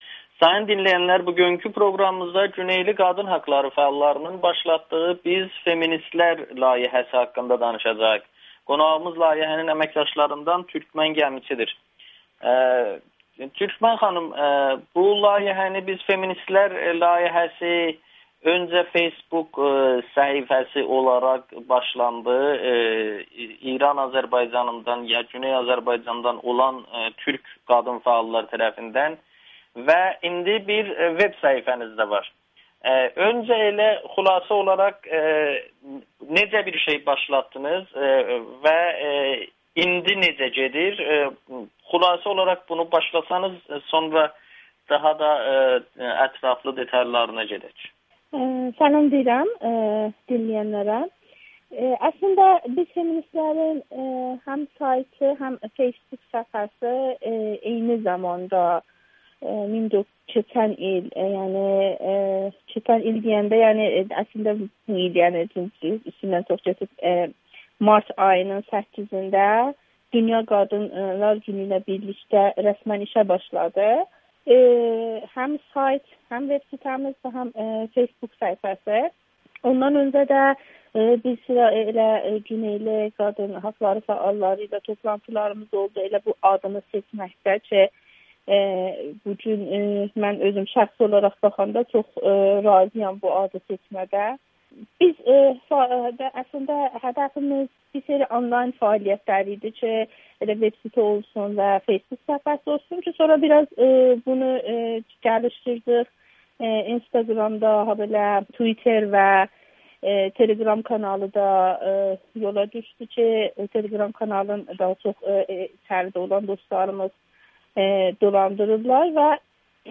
Güneyli fəalların “Biz Feministlər” layihəsi [Audio-Müsahibə]